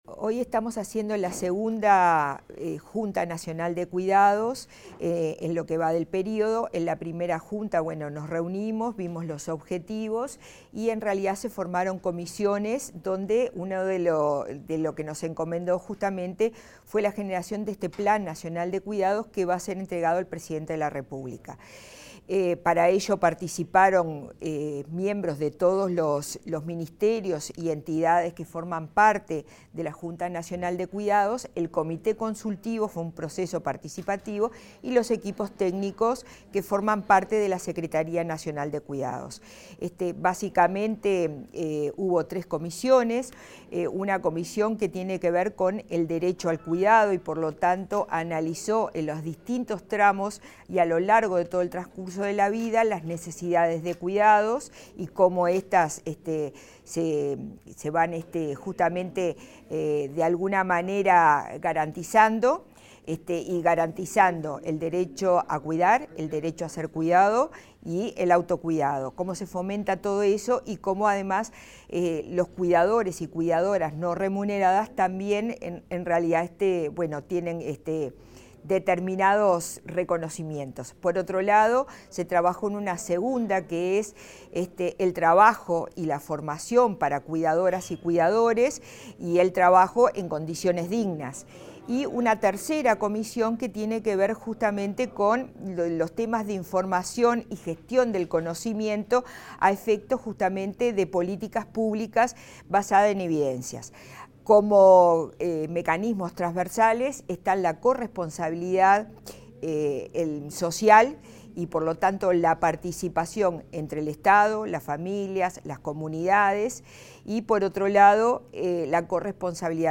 Declaraciones de la secretaria nacional de Cuidados, Susana Muñiz
La secretaria nacional de Cuidados, Susana Muñiz, realizó declaraciones a la prensa antes de la presentación del Plan Nacional de Cuidados 2026-2030.